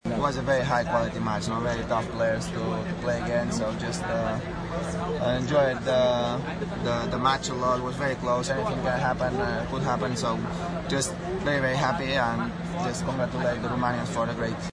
Rafael Nadal rövid nyilatkozata a mérkőzés után: